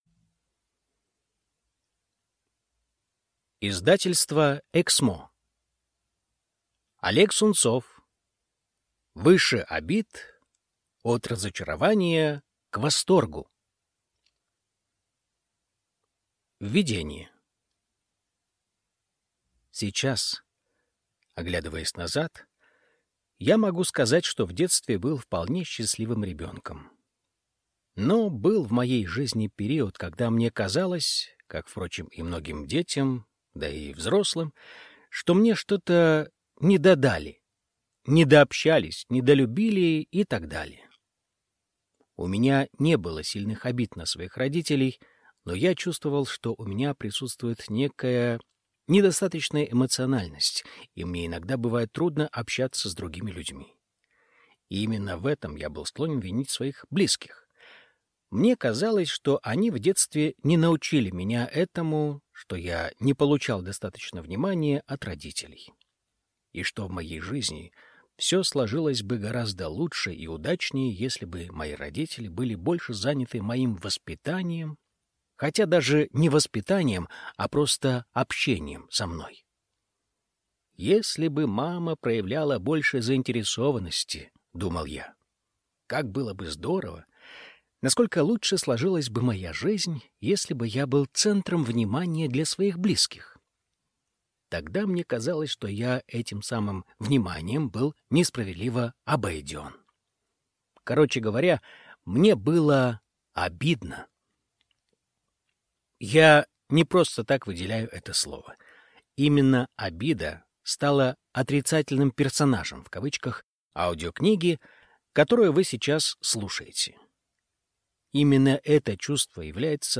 Аудиокнига Выше обид – от разочарования к восторгу | Библиотека аудиокниг